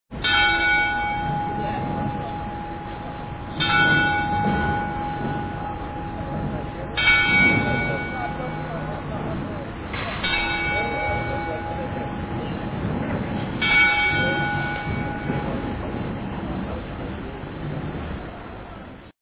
II. Bells
bell2.mp3